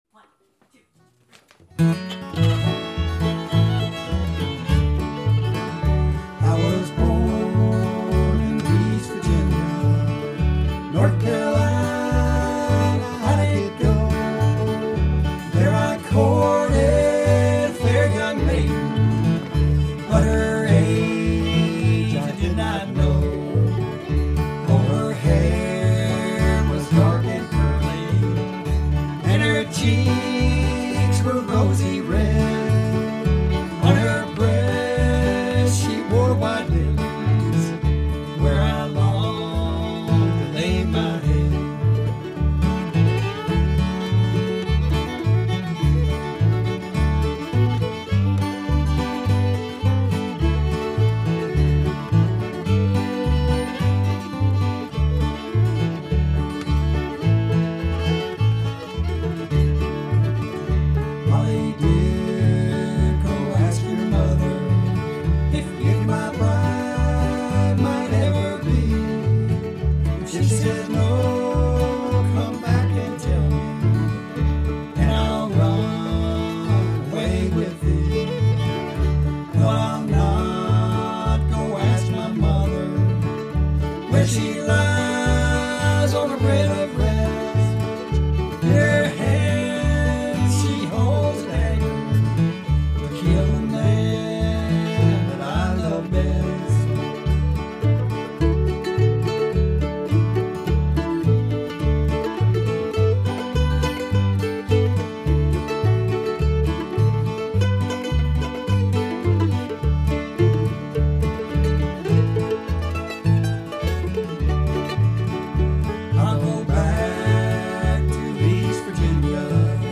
I download just the bass from SM to my iPad and import the file into the app. I use a mic thru the interface to add tracks for guitar, fiddle, mandolin, and vocals. The SM bass track provides awesome bass and acts like a metronome.